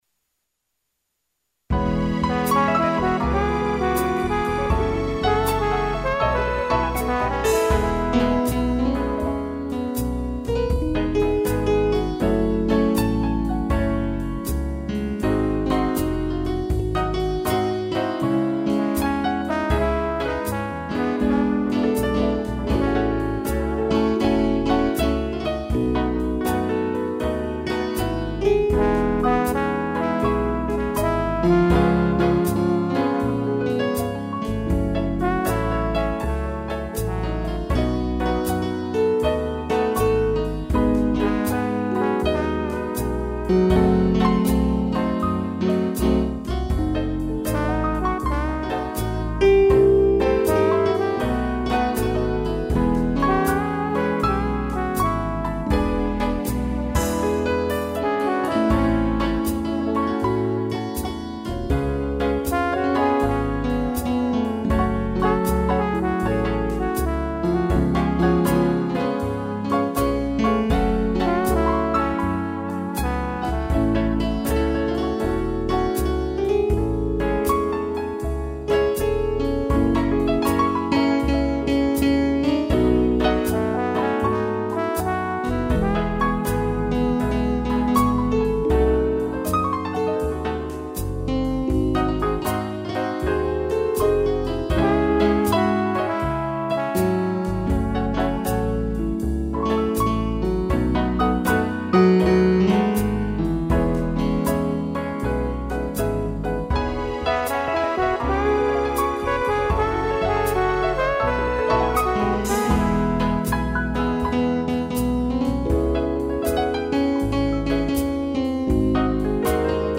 piano e trombone
instrumental